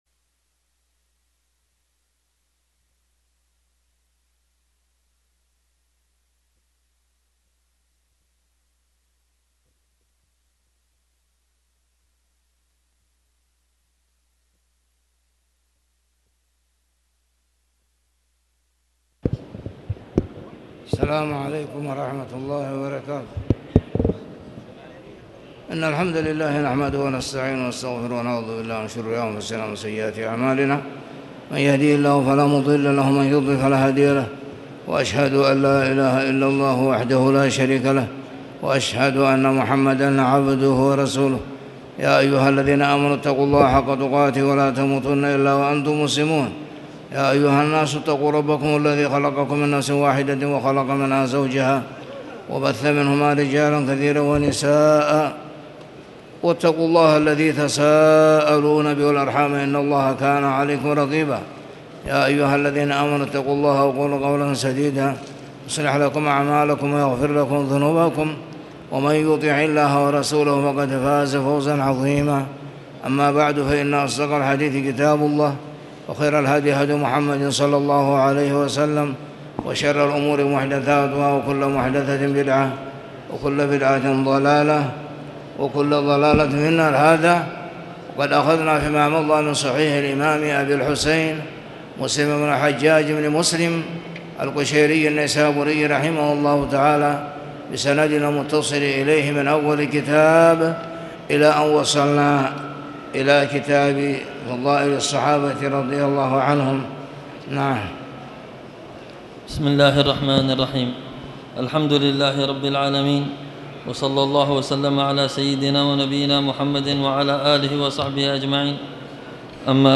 تاريخ النشر ٢٣ محرم ١٤٣٨ هـ المكان: المسجد الحرام الشيخ